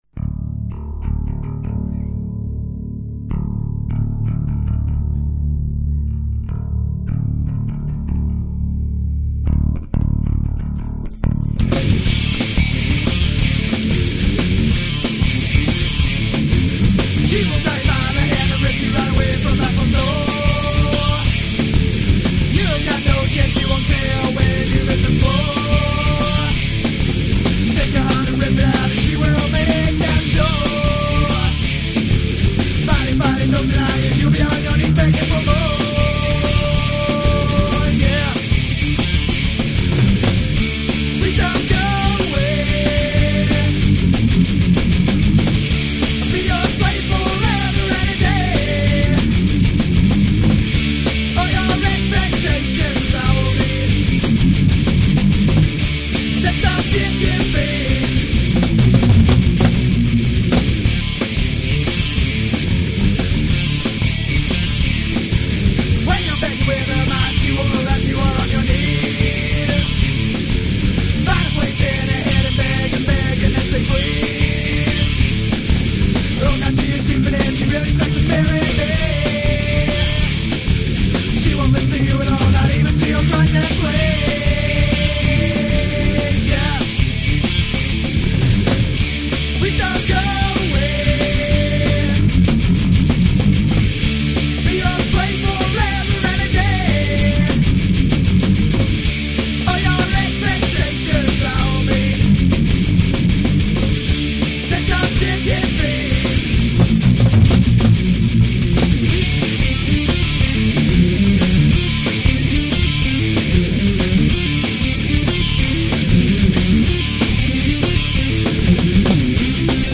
----Funk Rock Psycadelia----